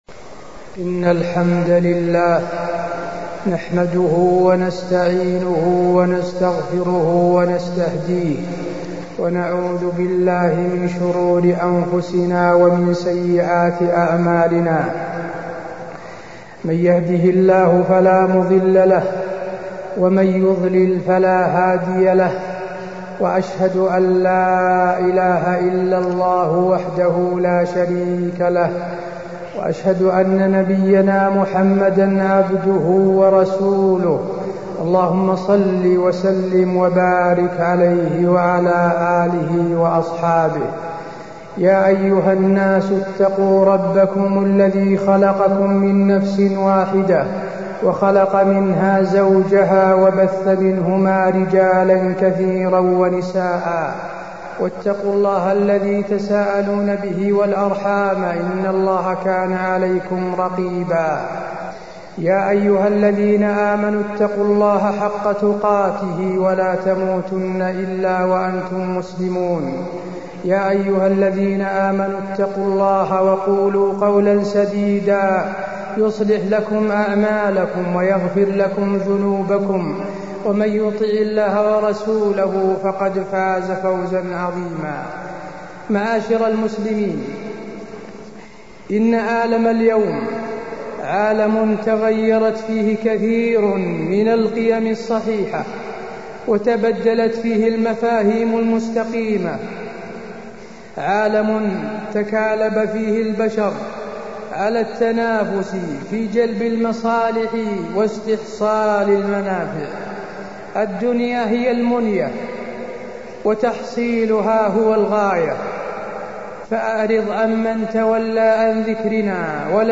تاريخ النشر ٤ رجب ١٤٢٥ هـ المكان: المسجد النبوي الشيخ: فضيلة الشيخ د. حسين بن عبدالعزيز آل الشيخ فضيلة الشيخ د. حسين بن عبدالعزيز آل الشيخ الكسب الحلال